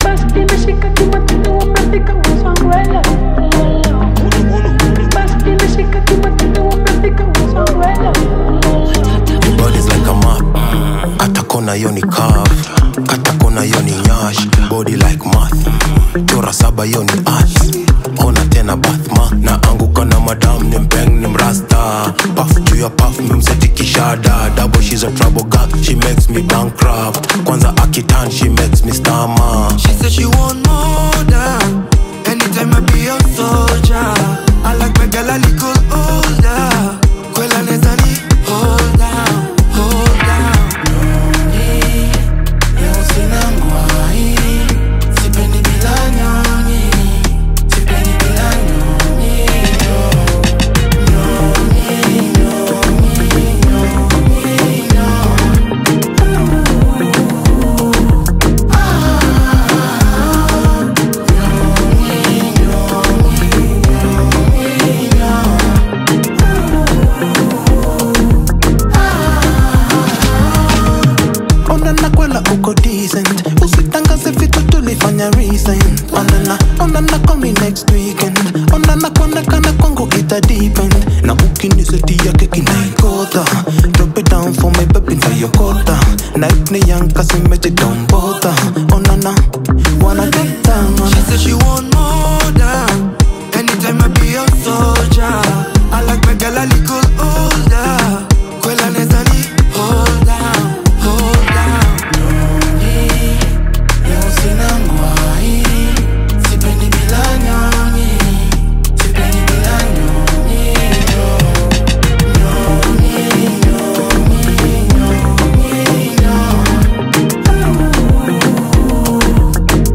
infectious Afro-Pop/Afro-dance collaboration
rhythmic Afro-dance grooves
smooth melodic vocals
Latest Bongo Flava, Afrobeat and more updates 🔥